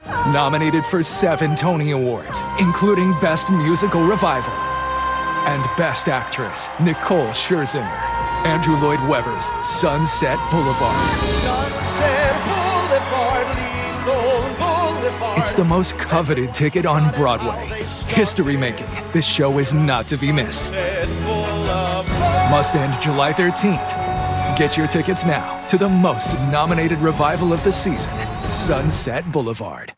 - Commercials Download This Spot